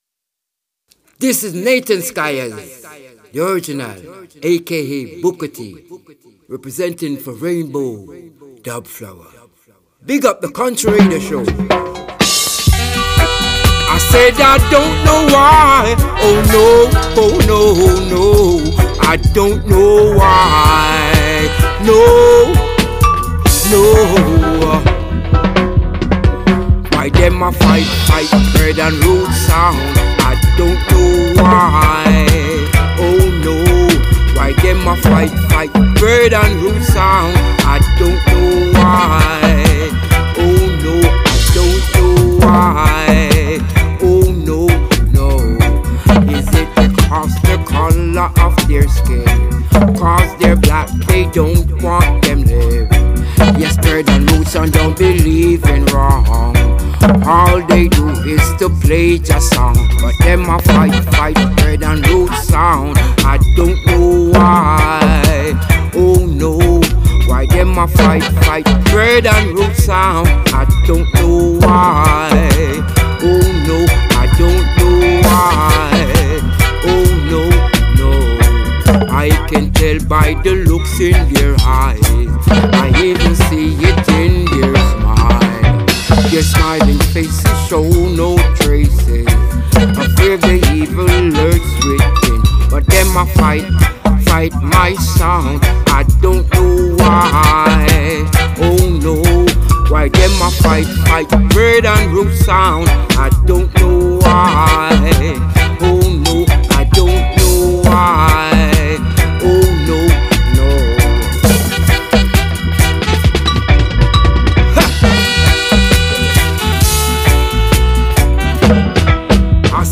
Roots Reggae
Voice